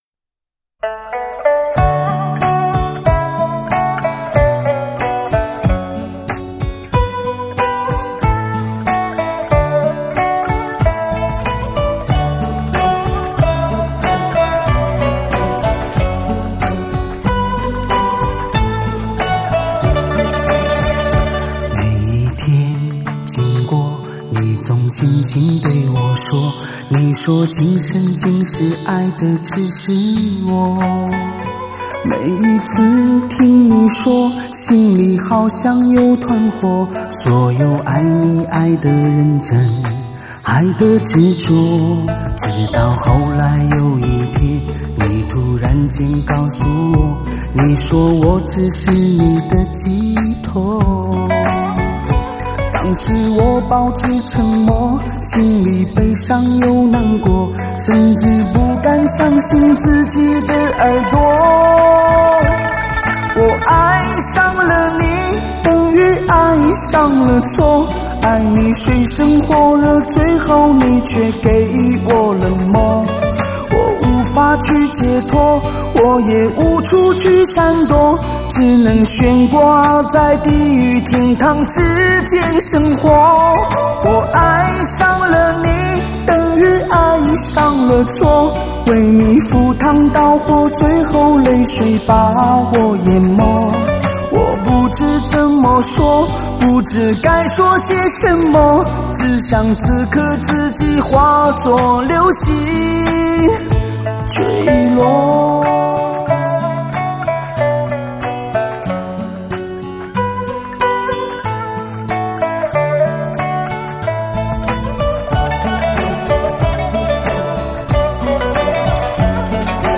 魅力男声悠然地将许多耳熟能详的旋律唱出新味道，音色极具男性魅力，
低吟浅唱，听来恰似一瓶年头久远的红酒，醇厚动人，沁人心脾，不胜回味。